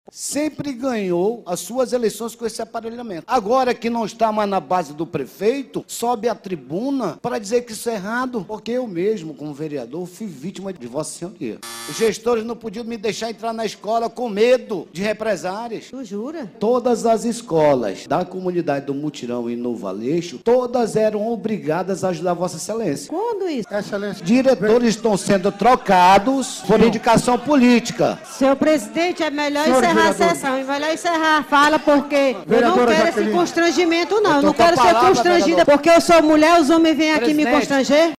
Os vereadores da Câmara Municipal de Manaus batem boca e trocam farpas durante sessão plenária nesta terça-feira, 27.